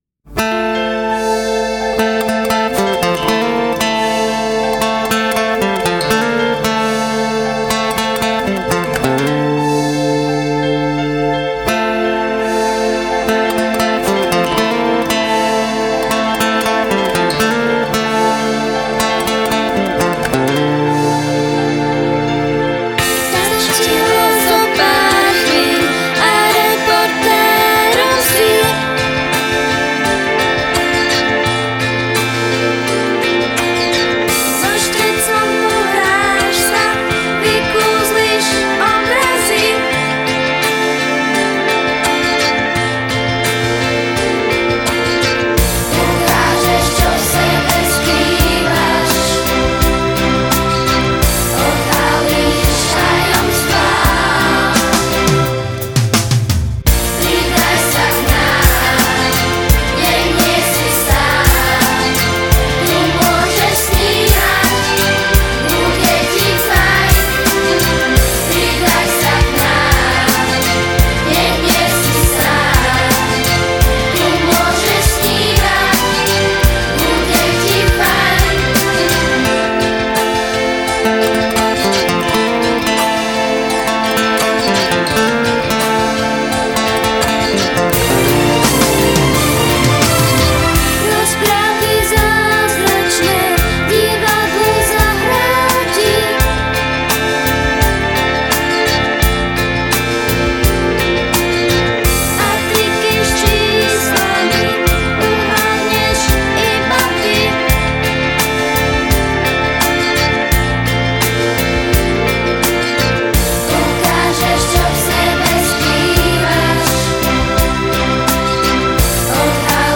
Pieseň naspievali bieli a modrí: